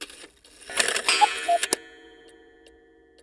cuckoo-clock-01.wav